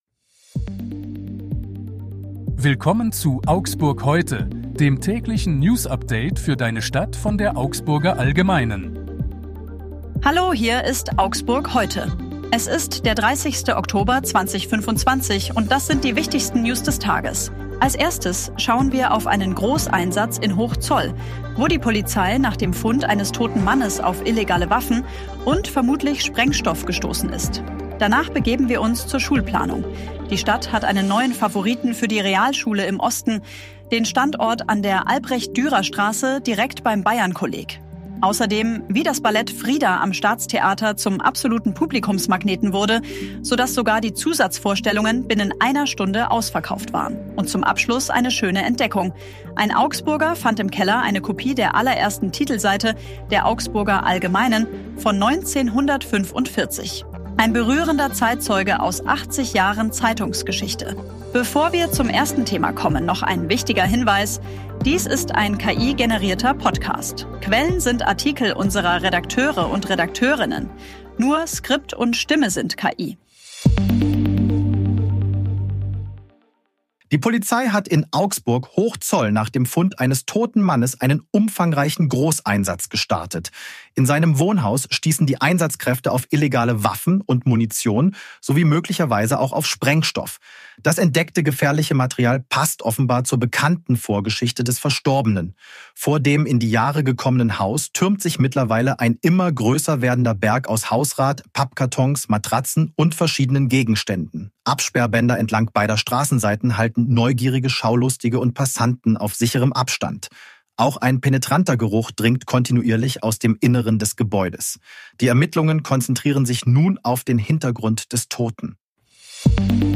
Nur Skript und Stimme sind KI.